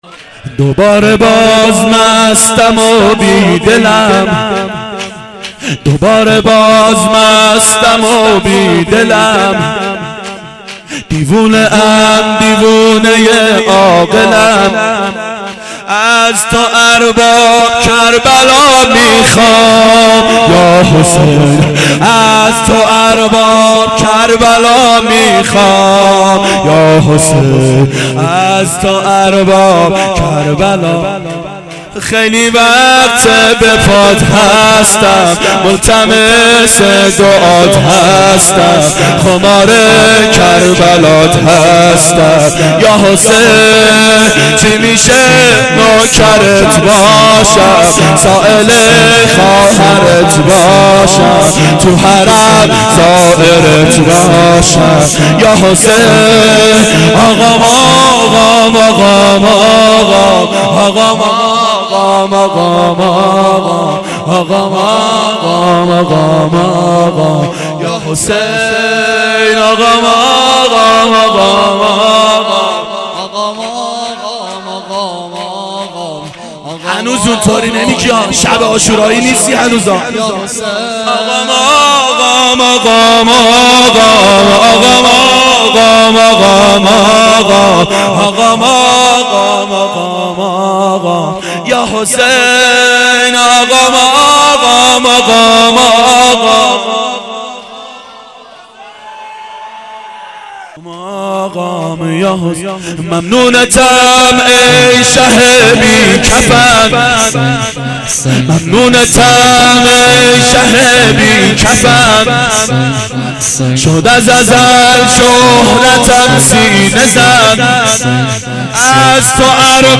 شب عاشورا 1390 هیئت عاشقان اباالفضل علیه السلام